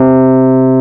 RHODES2S C3.wav